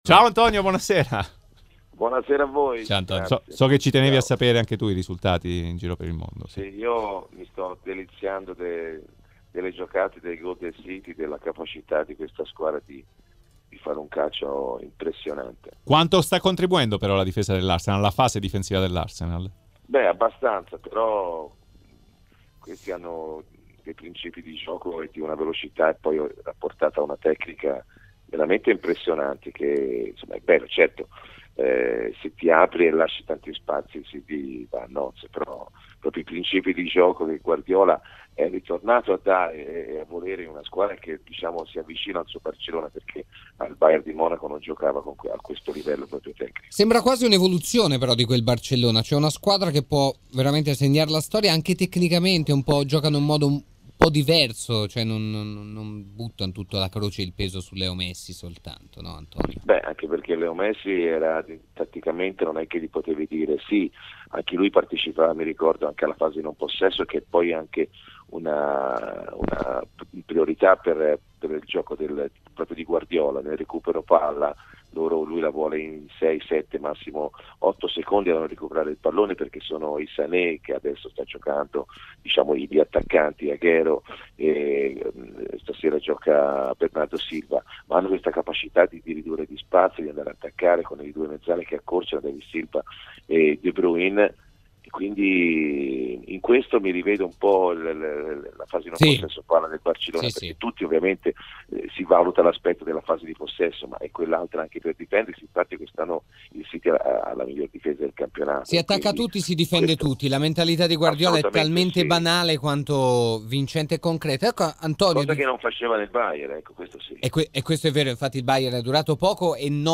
Antonio Di Gennaro, opinionista RMC Sport, su Arsenal-Manchester City e su Napoli-Roma.